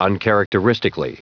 Prononciation du mot uncharacteristically en anglais (fichier audio)
Prononciation du mot : uncharacteristically